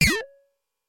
描述：通过Modular Sample从模拟合成器采样的单音。
标签： MIDI-速度-100 CSharp5 MIDI音符-73 挡泥板-色度北极星 合成器 单票据 多重采样
声道立体声